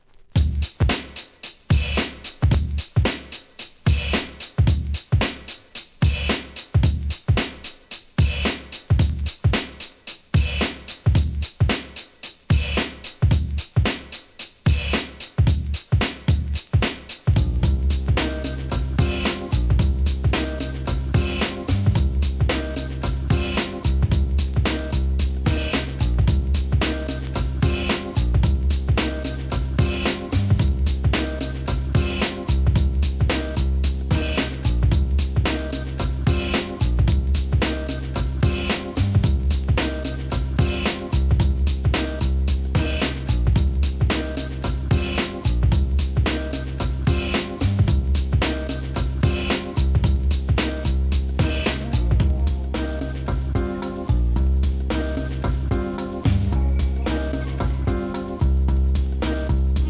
HIP HOP/R&B
ブレイクビーツEP